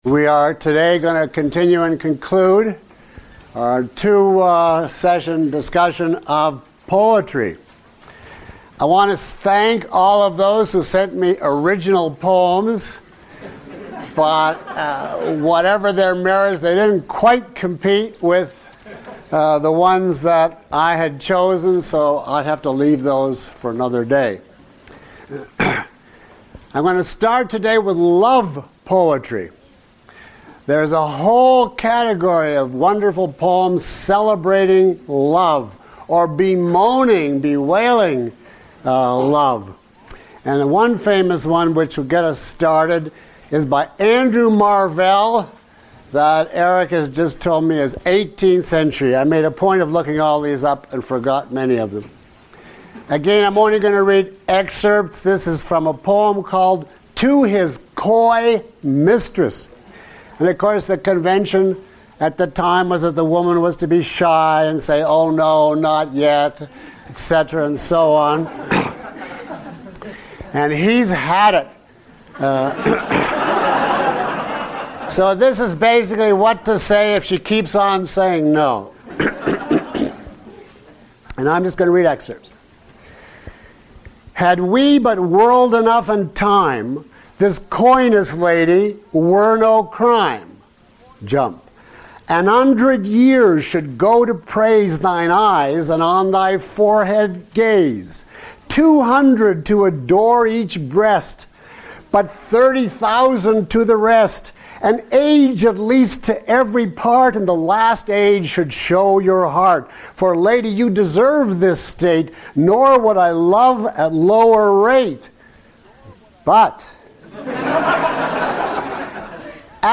He uses examples from some of his favorite poetry—read aloud in the lecture—to demonstrate how to analyze and understand great poems.